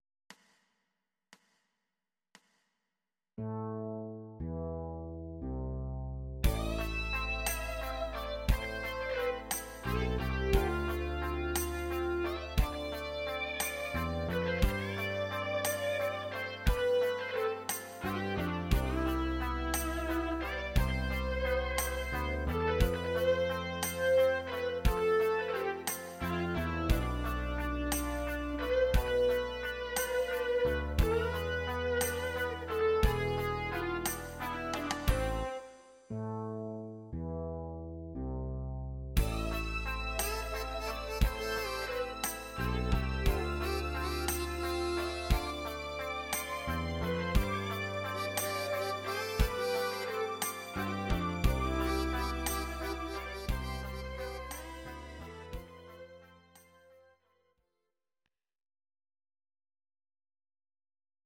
Audio Recordings based on Midi-files
Our Suggestions, Rock, 1970s